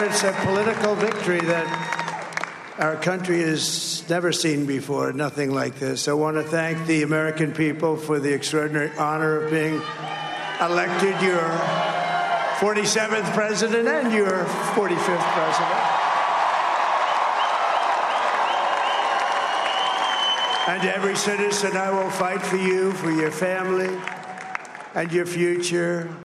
Flanked by his family, he’s addressing his supporters at the West Palm Beach Convention Centre in Florida to chants of ”USA”.
Donald Trump says he has made history: